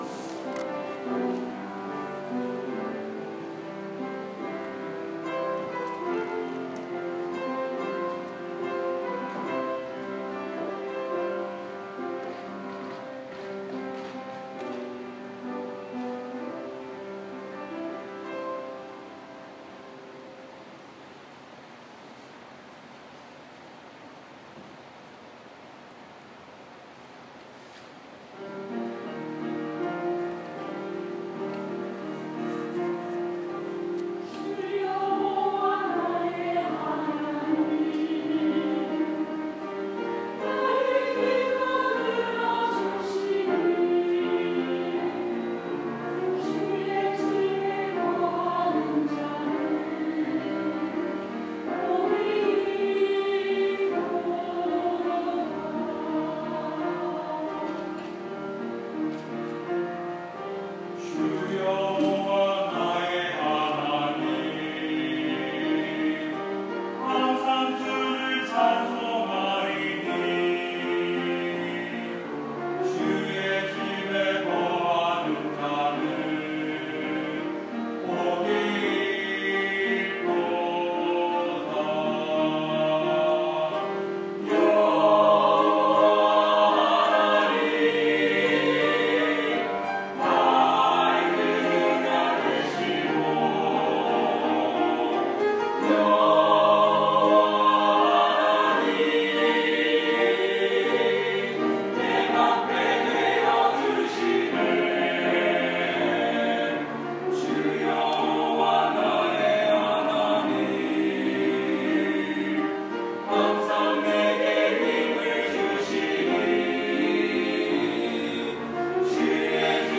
6월 12일 주일 찬양대 찬양(복이 있도다, 문구영곡)